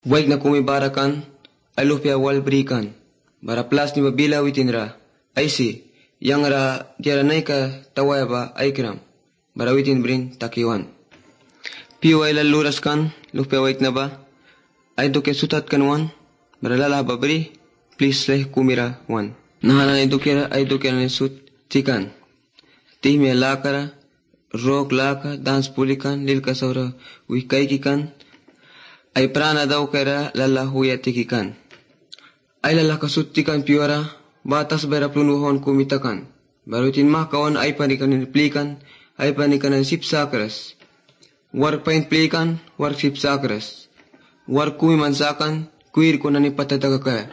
Some of the words of this possibly African/Caribbean-accented sentence sound English.